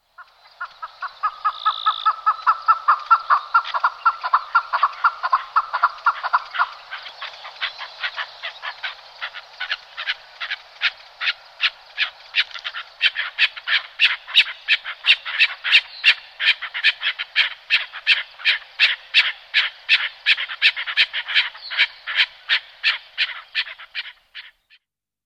Звуки кекликов в дикой природе